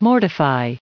1699_mortify.ogg